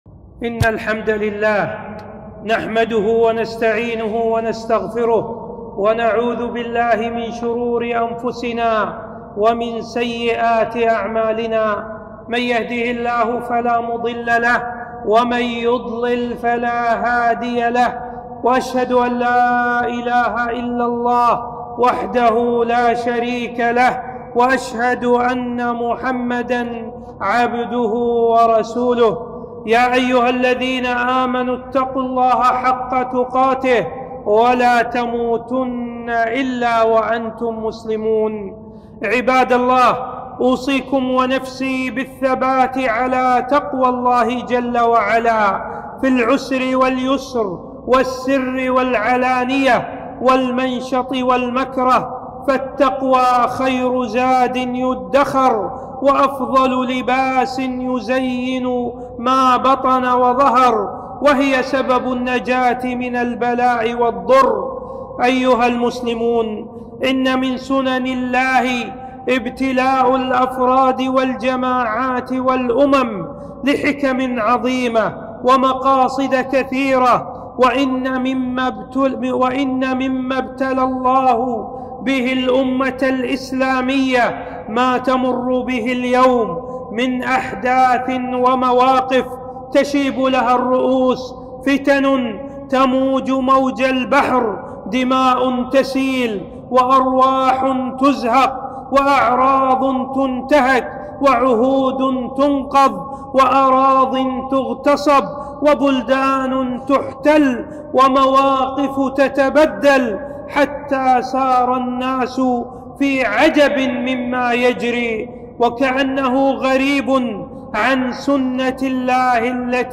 خطبة - ( إن تنصروا الله ينصركم )